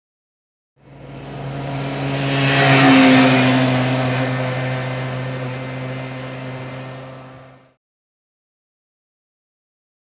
Moped; By; Moped Run Bye At 15 Mph Tr07